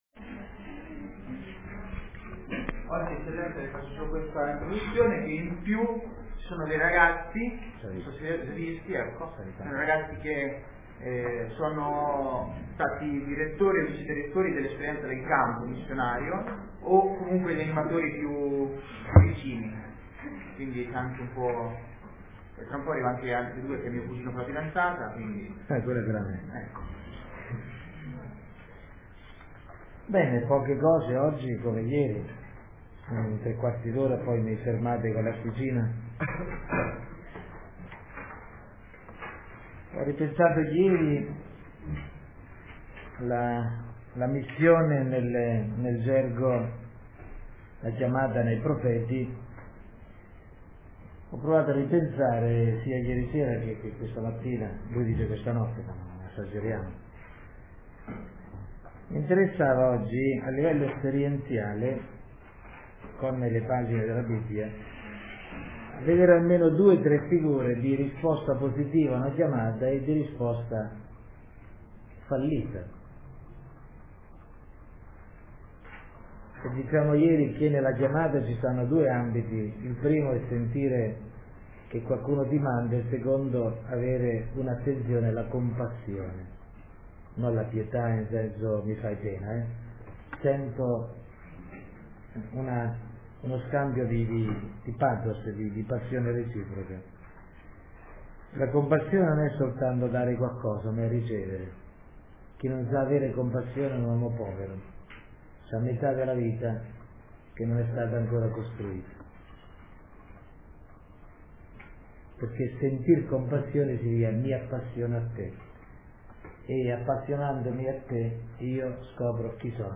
Riflessione ai giovani del CMD